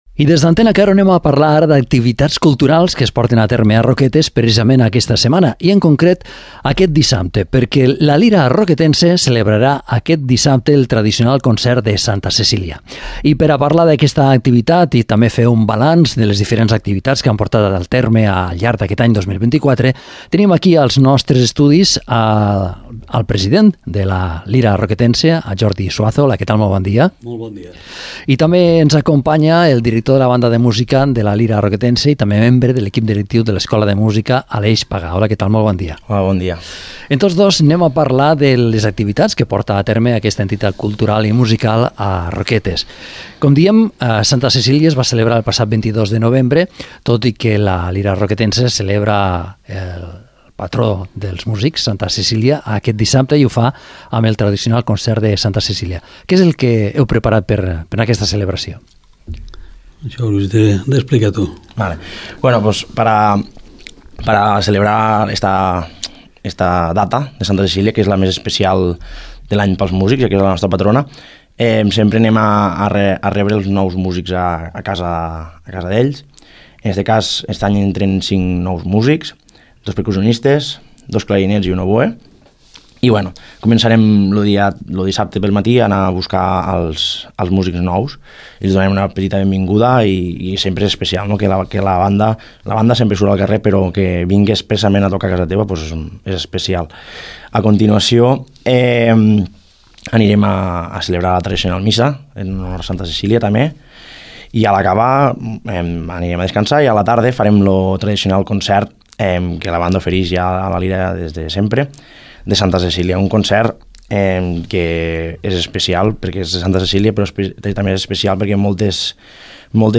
Concert de Santa Cecília de La Lira Roquetense a favor d’una banda de València afectada per la DANA.